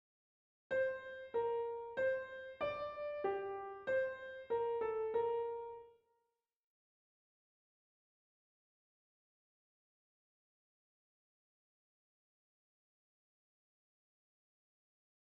A la mesure 2, la seconde voix donne ce que l’on appelle la réponse, c’est à dire le sujet mais transposé une quarte au dessus, ici dans le ton de sol, dominante de do.